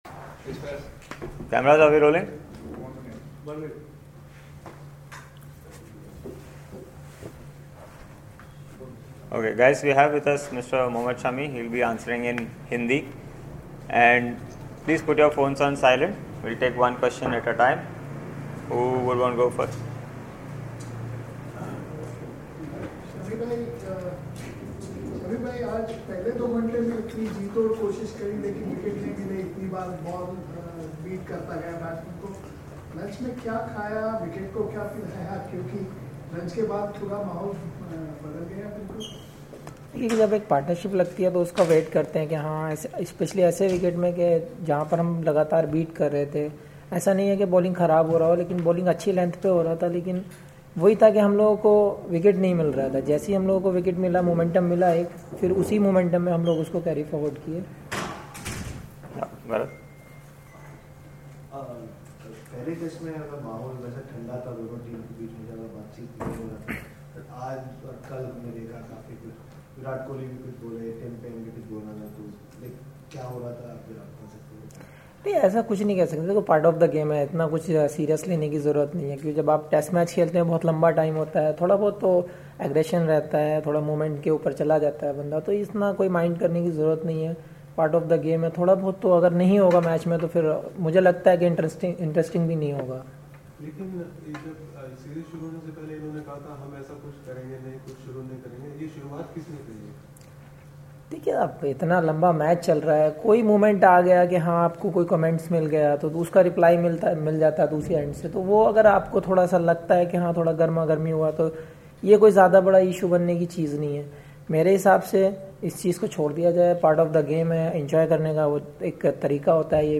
Mohammed Shami, Member, Indian Cricket Team, speaks with the media on Monday, December 17 in Perth after Day 4 of the 2nd Test against Australia.